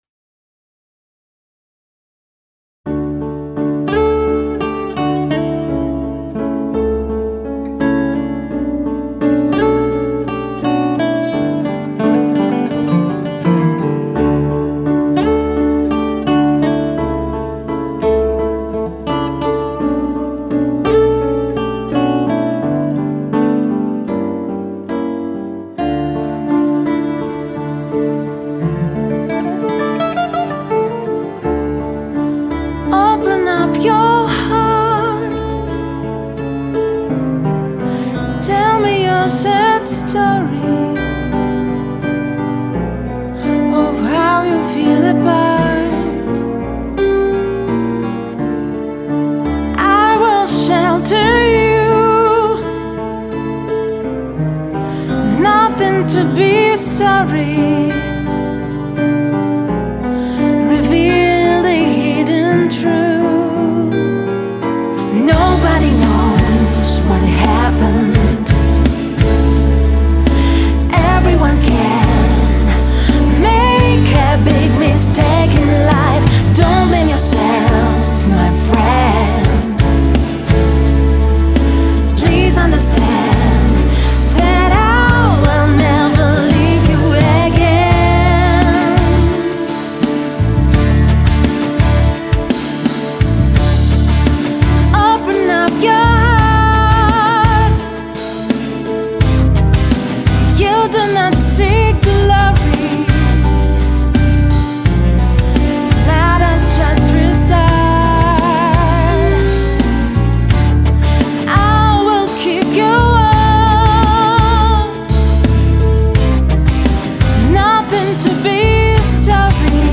Новый трэк на выходные и начало осени для отдыха и созерцания